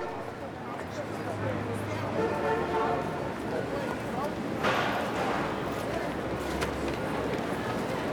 streetnoise.wav